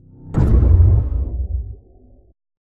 flash_shield.ogg